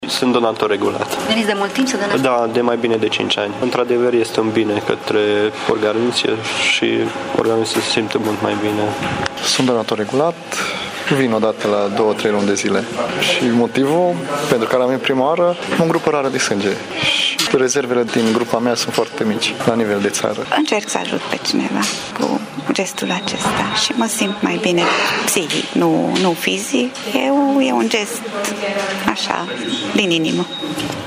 Astăzi, în prima zi de campanie, studenții au lipsit din cauza cursurilor, însă au venit alți tineri cu grupe rare de sânge, ce sunt deja donatori onorifici: